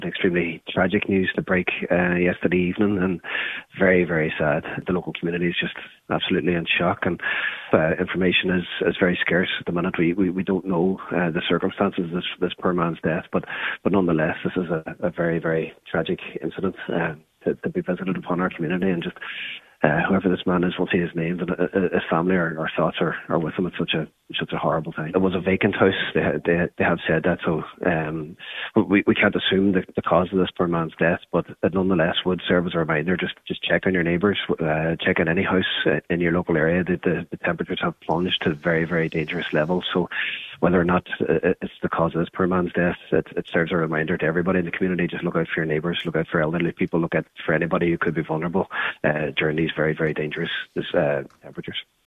Cathaoirleach of the Inishowen Municipal District, Councillor Jack Murray says its important that people check on neighbours during the current cold snap: